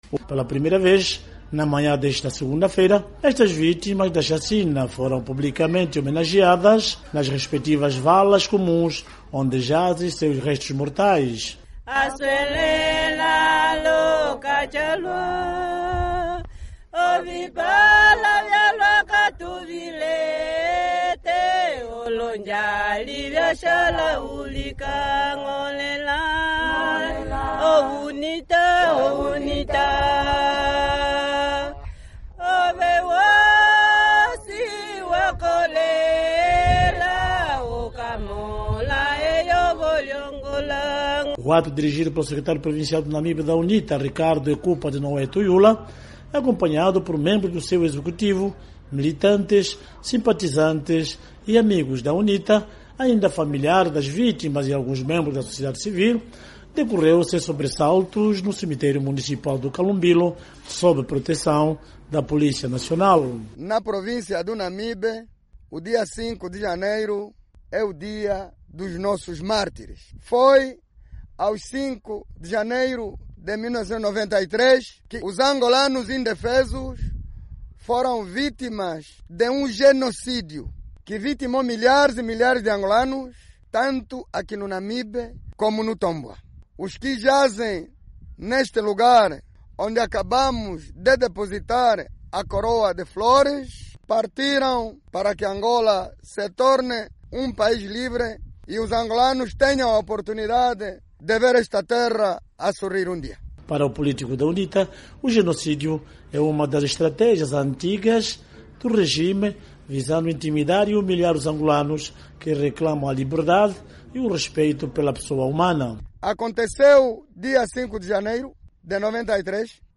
As mulheres entoaram cânticos mas também choraram pela alma dos entes queridos, cuja vida foi amputada prematuramente pelo simples factos de falarem e pensarem diferente.
No local, várias pessoas prestaram o seu testemunho, falando o que viram e sentiram no pretérito 5 de Janeiro de 1993, tanto na cidade do Namibe, como no município piscatório do Tombwa.